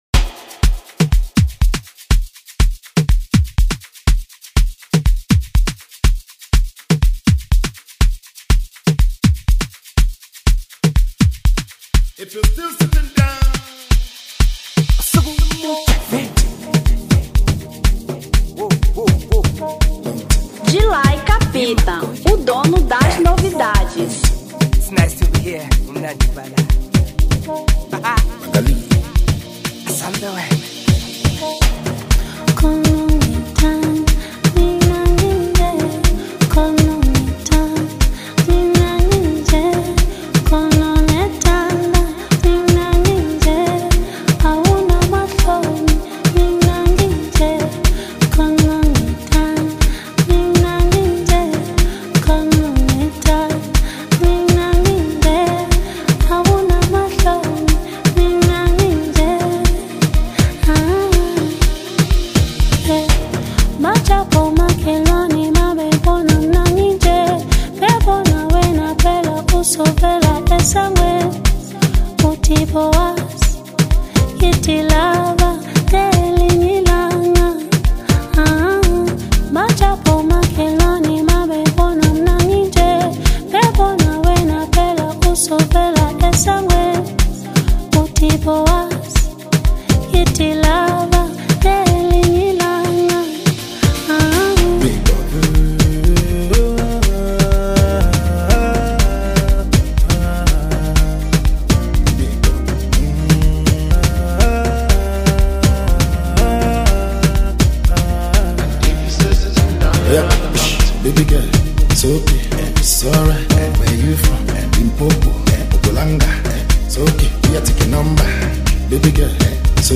Amapiano 2023